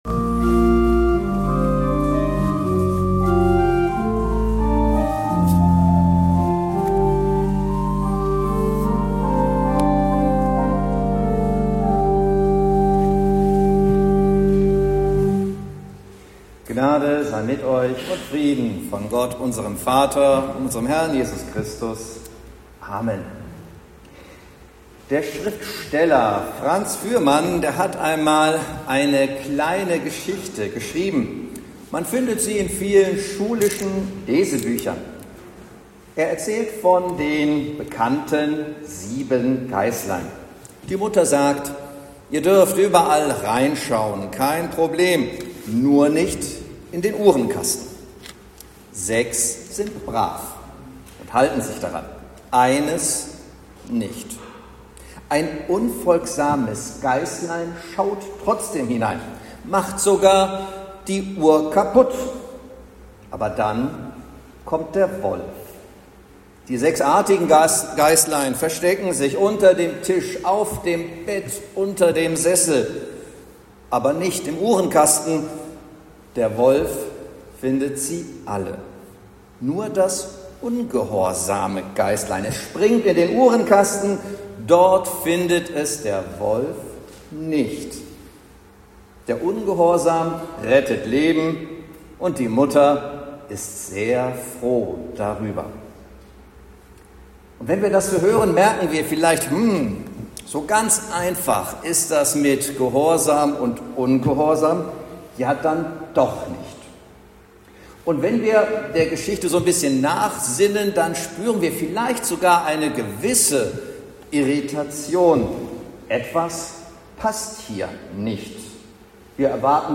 Predigt zu Invokavit